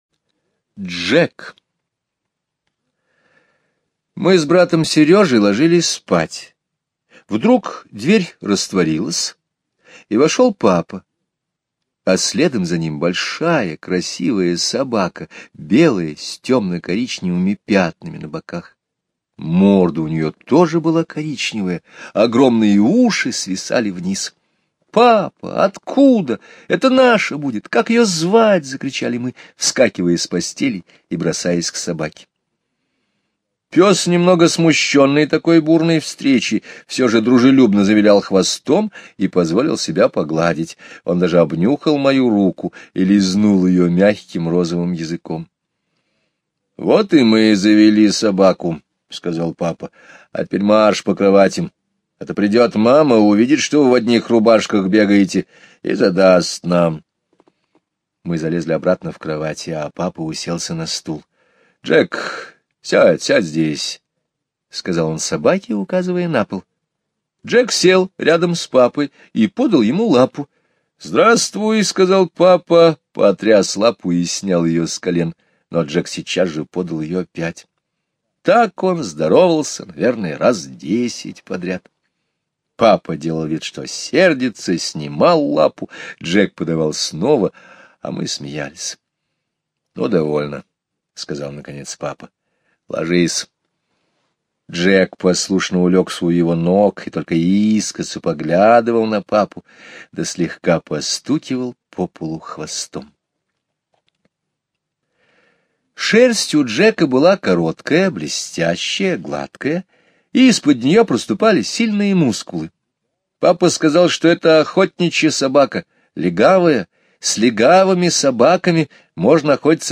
Слушайте аудио рассказ "Джек" Скребицкого Г. онлайн на сайте Мишкины книжки.